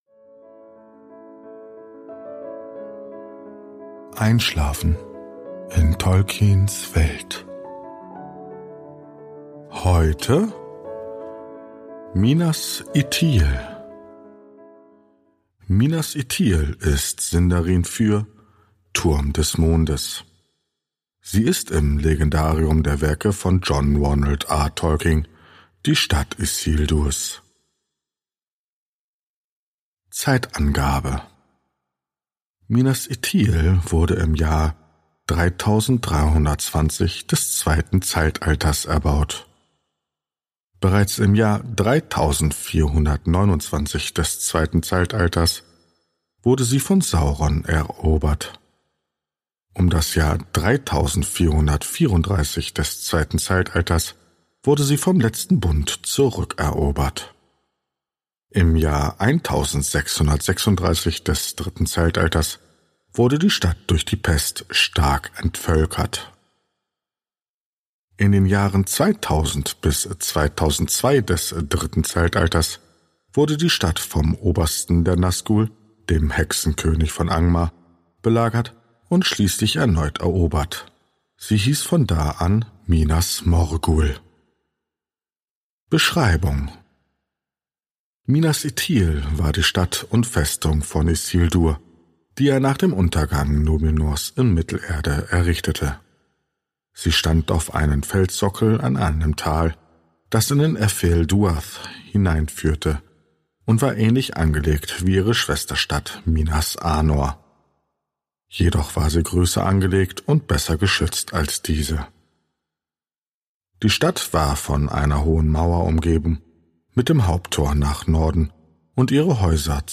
Gutenachtgeschichten aus der Ardapedia